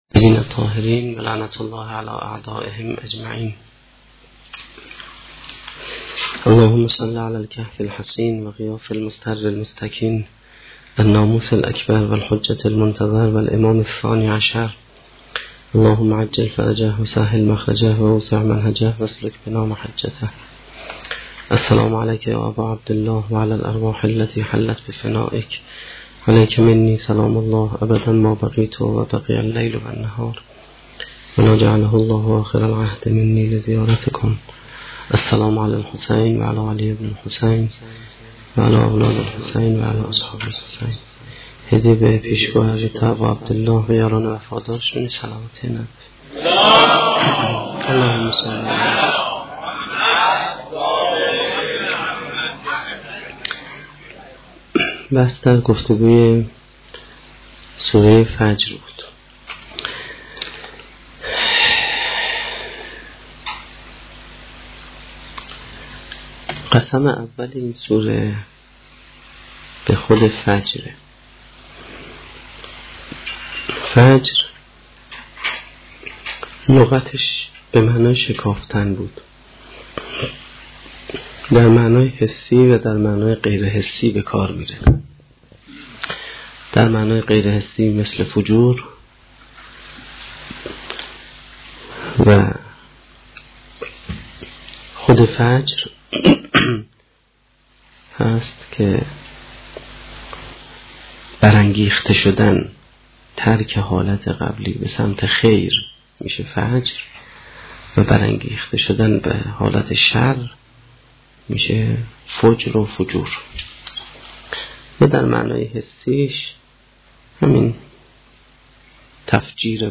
سخنرانی هشتمین شب دهه محرم1435-1392